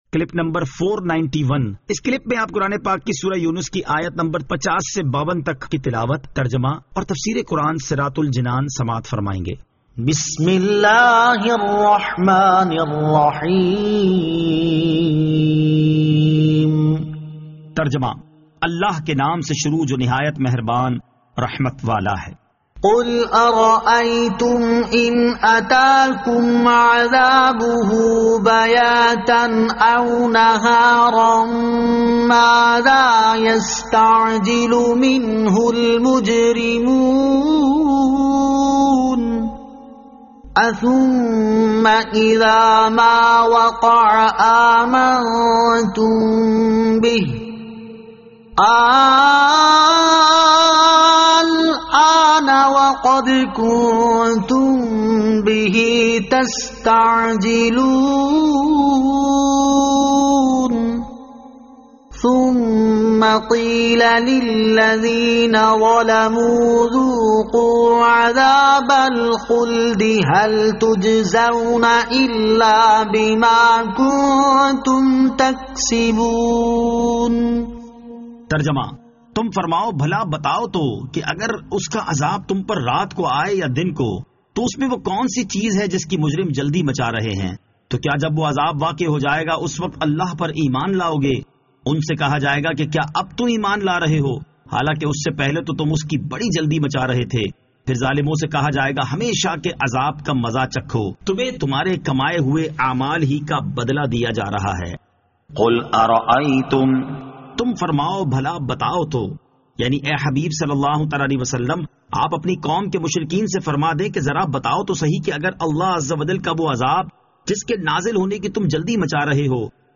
Surah Yunus Ayat 50 To 52 Tilawat , Tarjama , Tafseer
2021 MP3 MP4 MP4 Share سُوَّرۃُ یُونُس آیت 50 تا 52 تلاوت ، ترجمہ ، تفسیر ۔